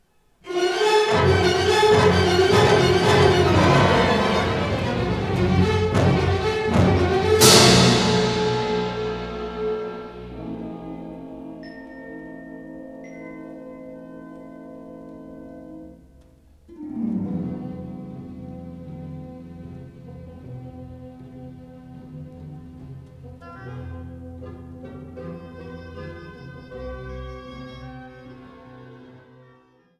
Con fuoco e con strepito - Molto moderato